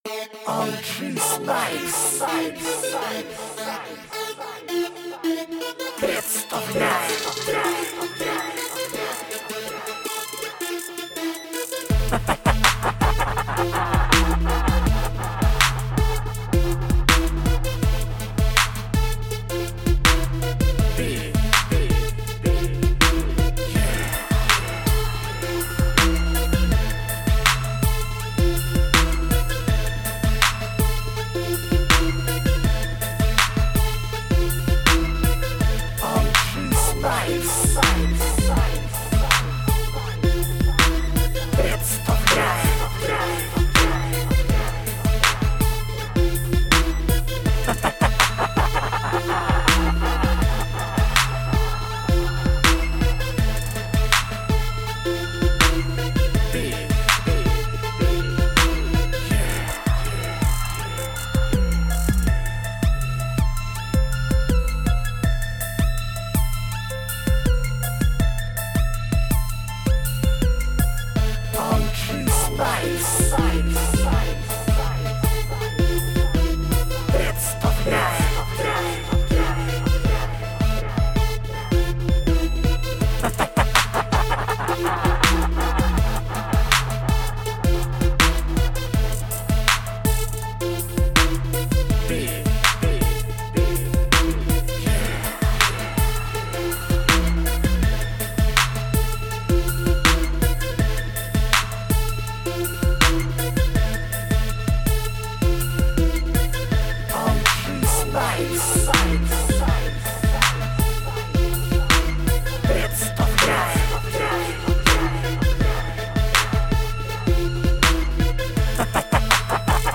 TRap Beat Underground Victory